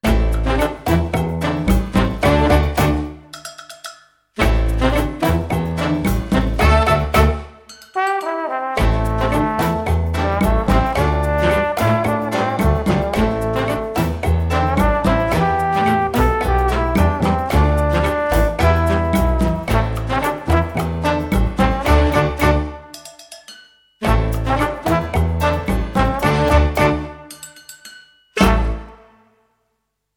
Title music for TV series on fishing.